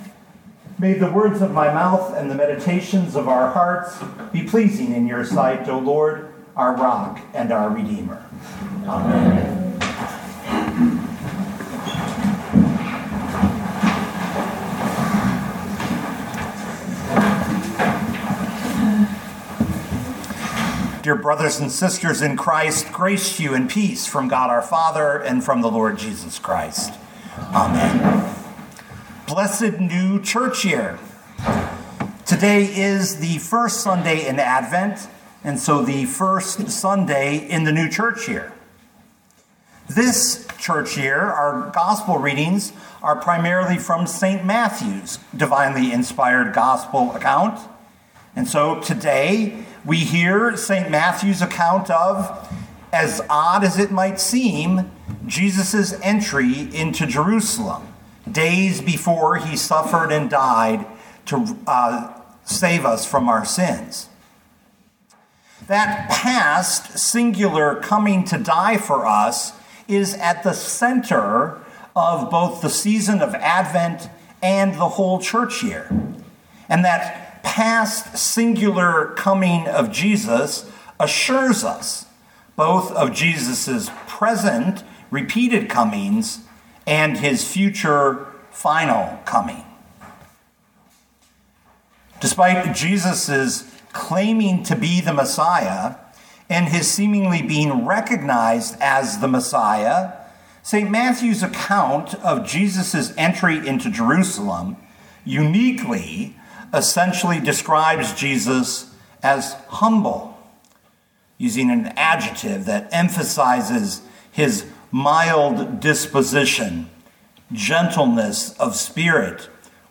2025 Matthew 21:1-11 Listen to the sermon with the player below, or, download the audio.